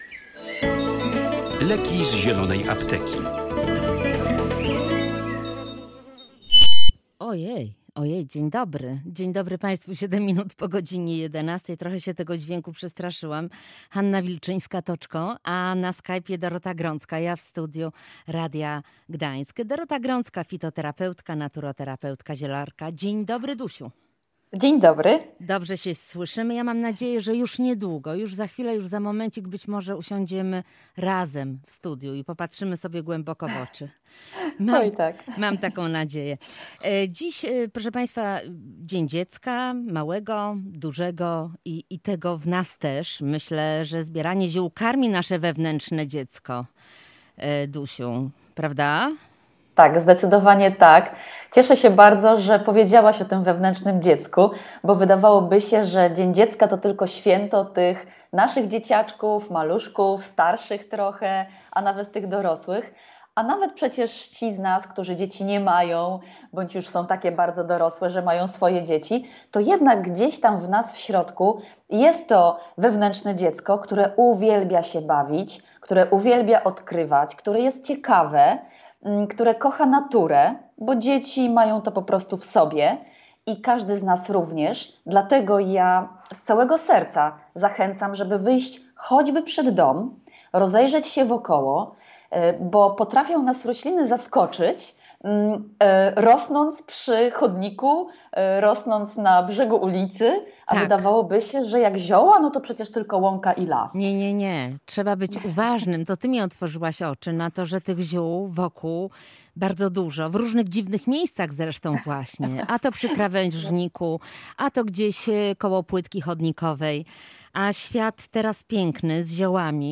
Jak się okazuje bardzo pomocna może być bazylia. O sposobach przygotowania, działaniu oraz różnych formach ziół dla dzieci rozmawialiśmy w audycji „Zielarka w Radiu Gdańsk”.